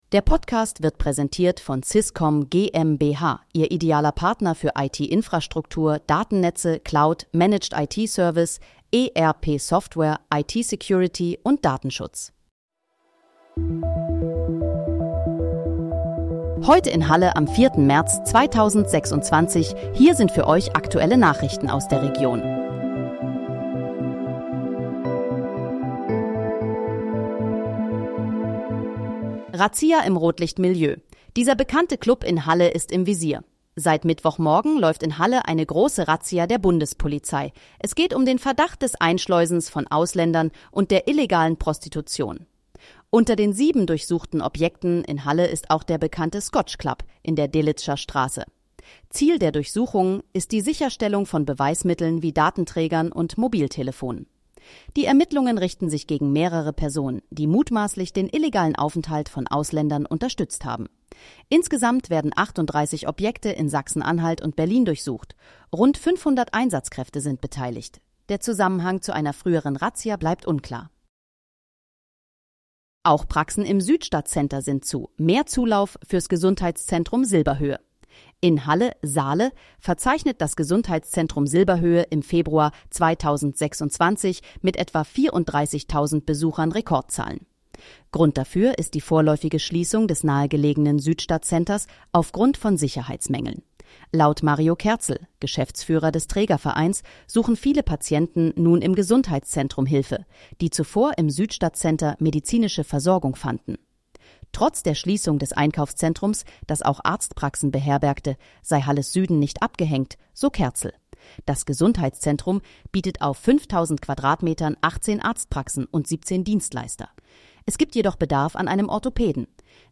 Heute in, Halle: Aktuelle Nachrichten vom 04.03.2026, erstellt mit KI-Unterstützung